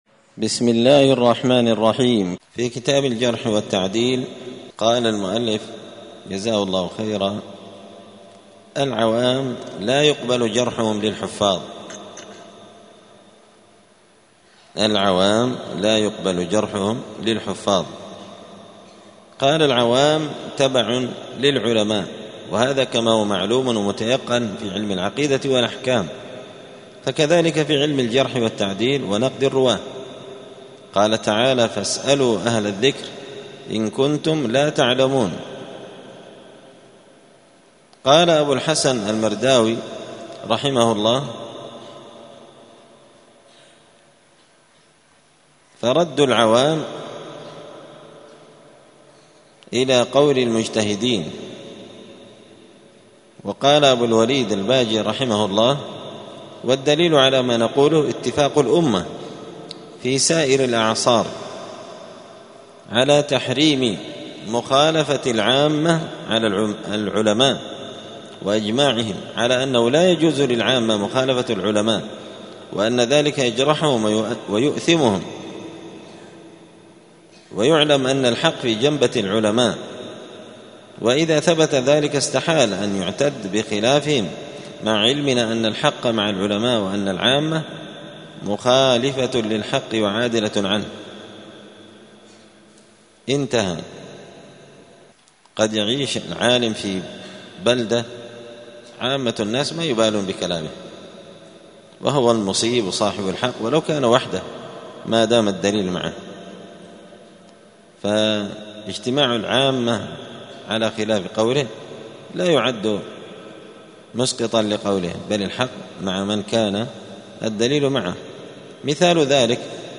*الدرس الثامن و الستون (68) باب العوام لا يقبل جرحهم في الكفار*